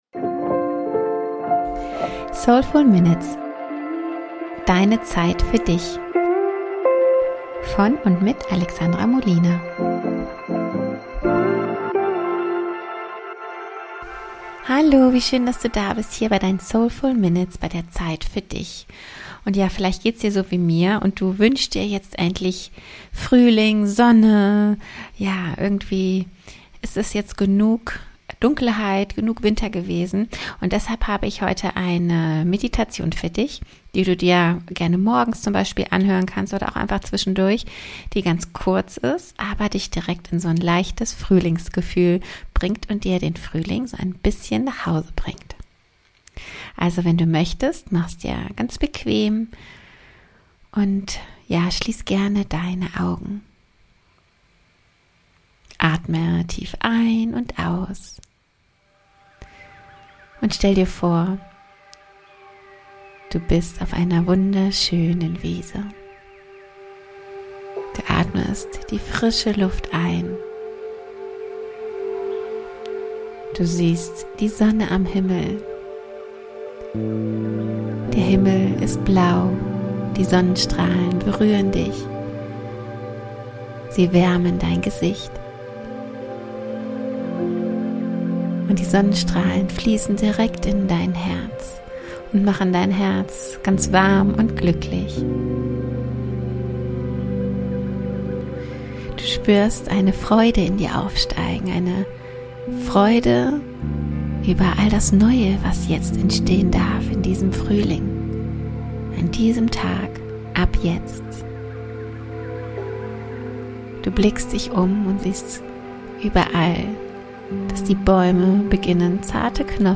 eine wunderschöne Meditation